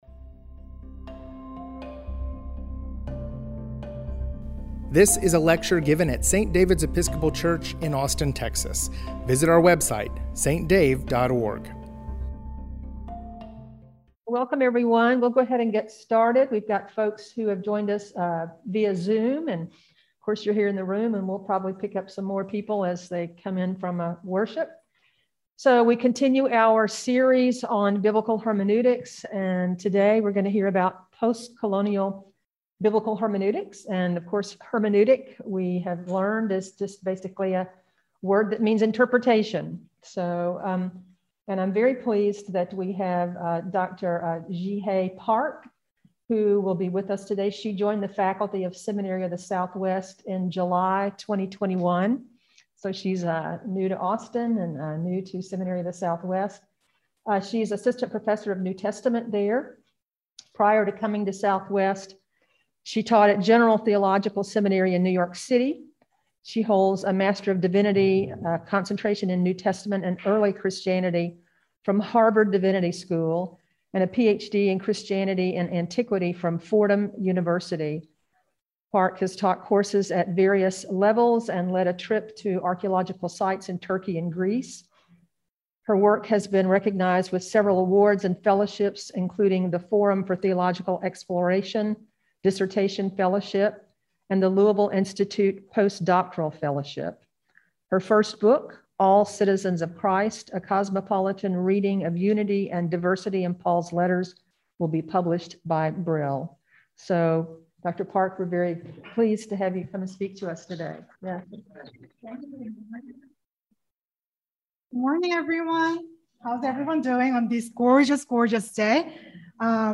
Biblical Hermeneutics Lecture Series: Post-Colonial Biblical Hermeneutics